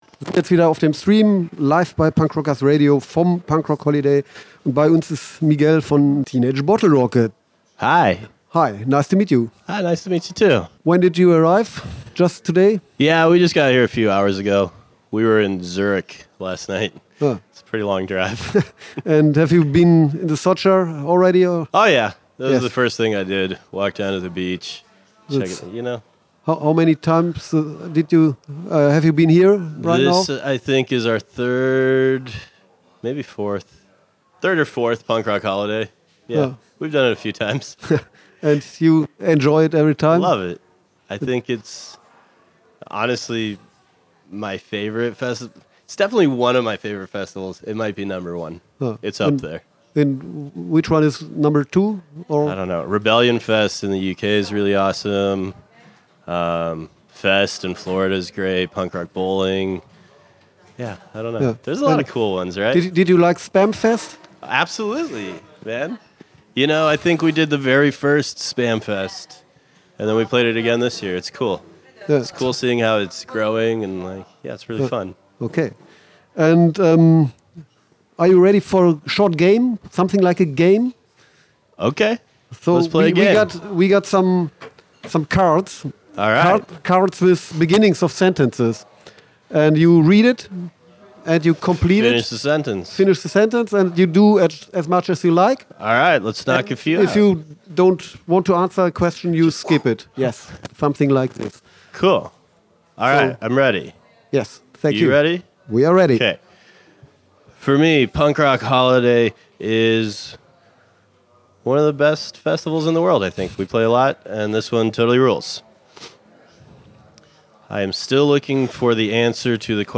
Teenage Bottlerocket – Interview @ Punk Rock Holiday 1.9
teenage-bottlerocket-interview-punk-rock-holiday-1-9-mmp.mp3